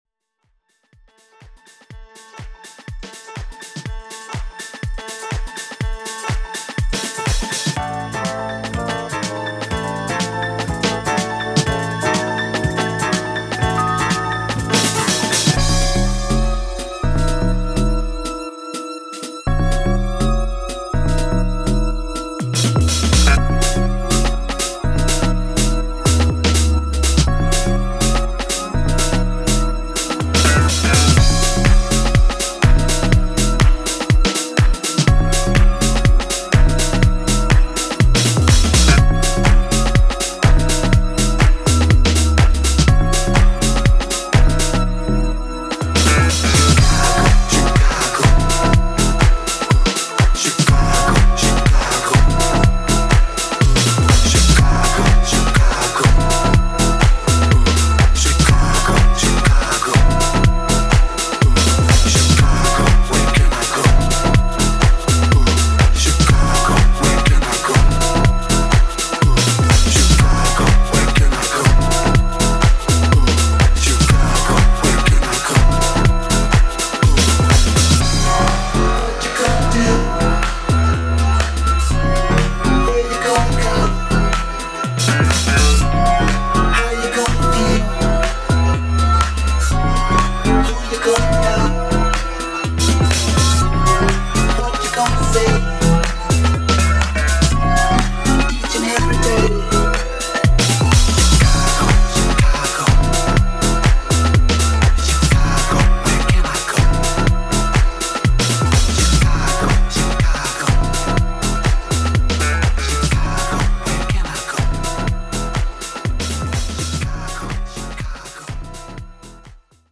jazz disco
catchy, toe tappin’ dancefloor friendly groove